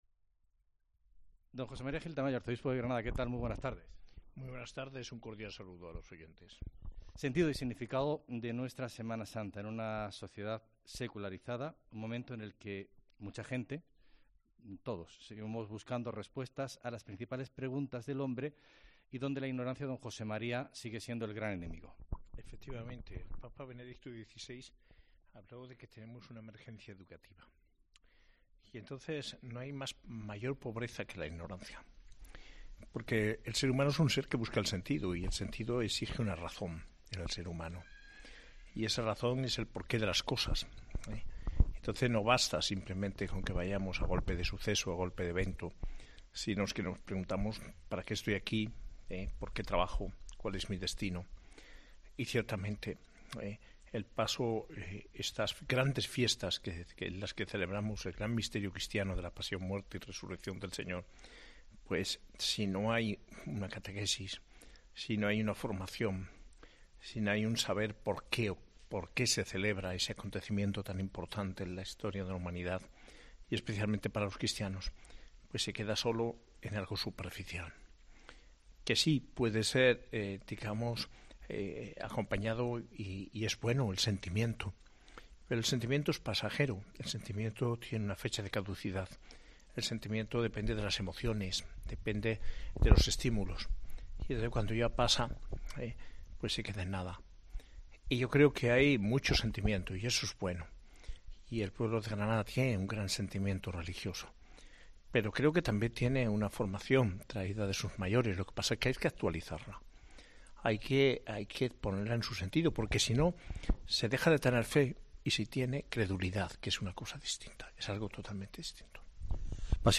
Entrevista al Arzobispo de Granada, monseñor José María Gil Tamayo